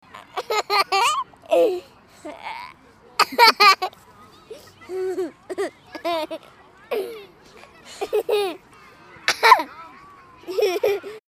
Four-year-old child laughing